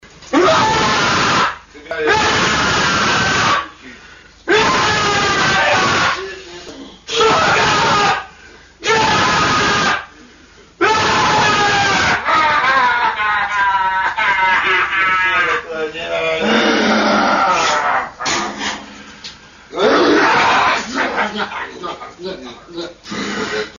Звуки крика людей
Крики безумца